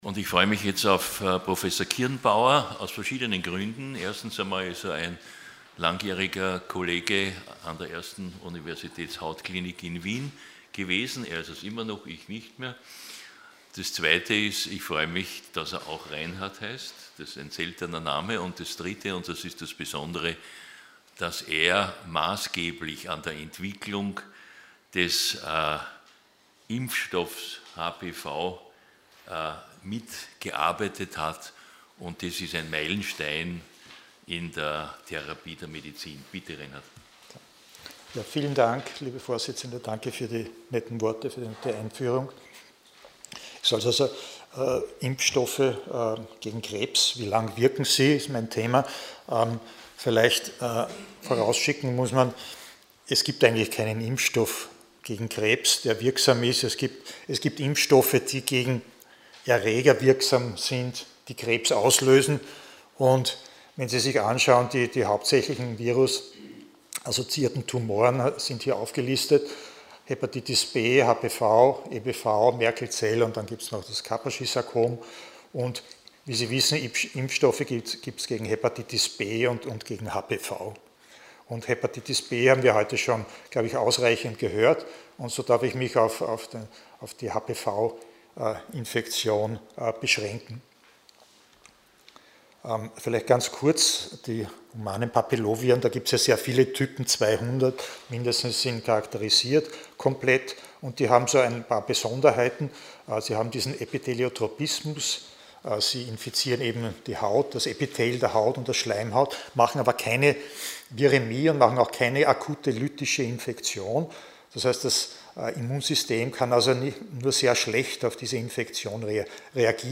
Sie haben den Vortrag noch nicht angesehen oder den Test negativ beendet.
Hybridveranstaltung